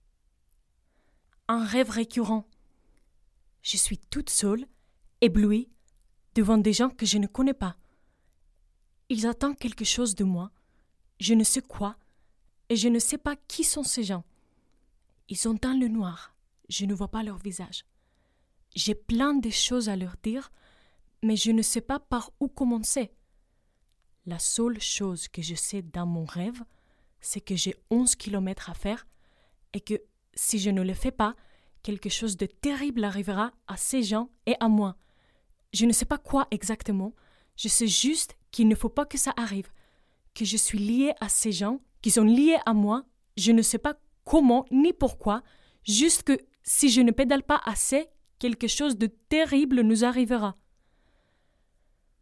- Contralto